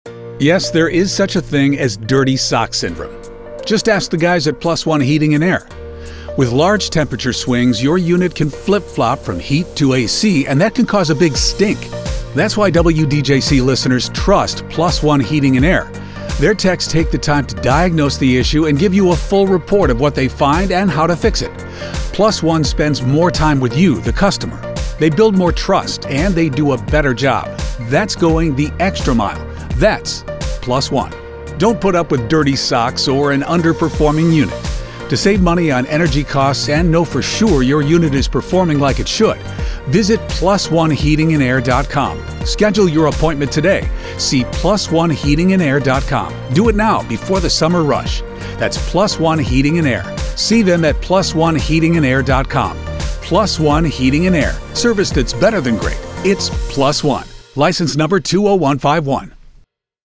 Our current commercials: